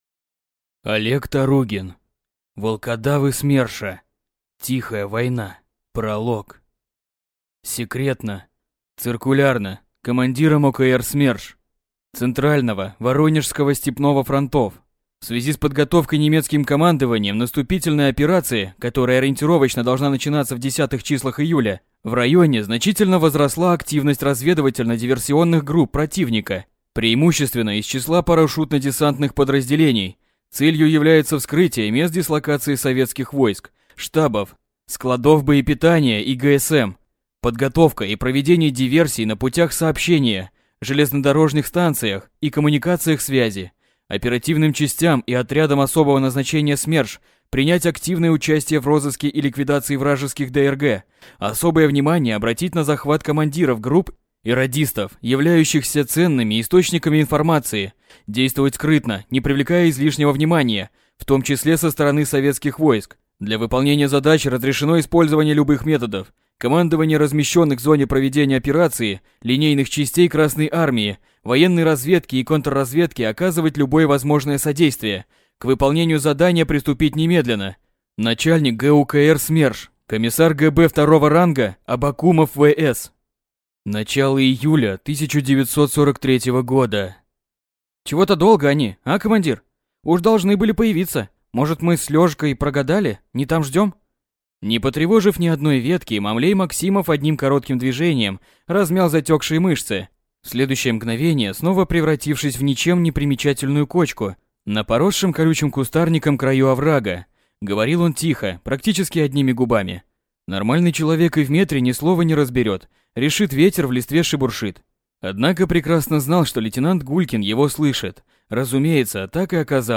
Аудиокнига Волкодавы СМЕРШа. Тихая война | Библиотека аудиокниг
Прослушать и бесплатно скачать фрагмент аудиокниги